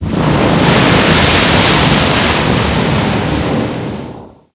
lavasplash.wav